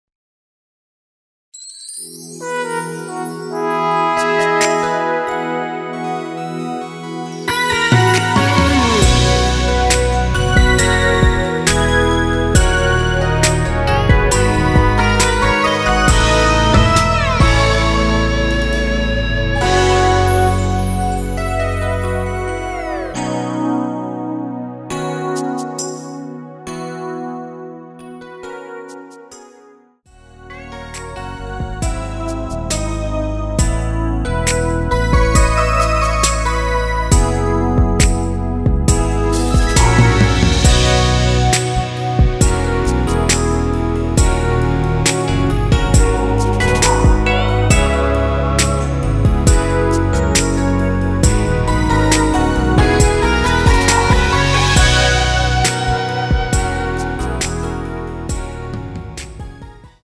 원키에서(-8)내린(짧은편곡) MR입니다.
앞부분30초, 뒷부분30초씩 편집해서 올려 드리고 있습니다.
중간에 음이 끈어지고 다시 나오는 이유는